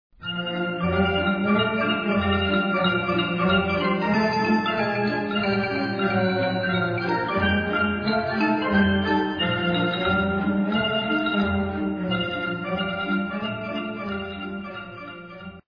signature tune